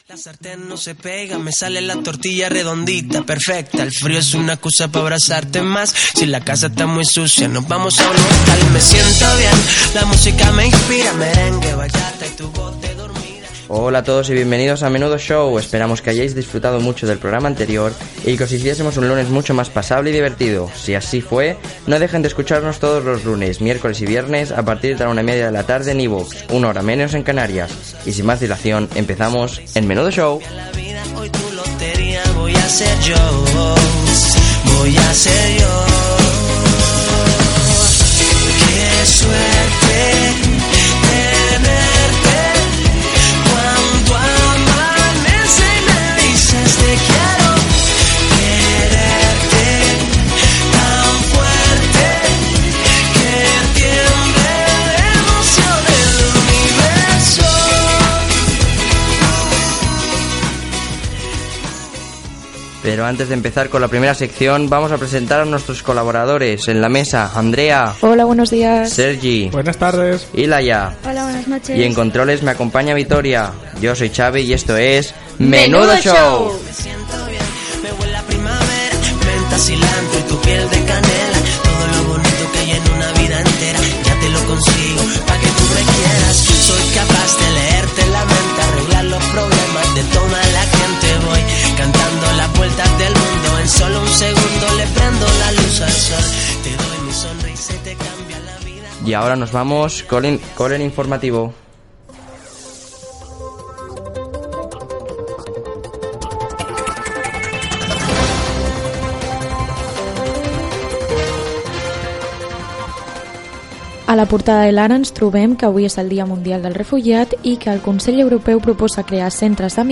Presentació, equip, informatiu (dia mundial del refugiat, Trump visitarà Espanya, esports), la Copa Mundial de la FIFA Rusia 2018 (futbol masculí) Gènere radiofònic Entreteniment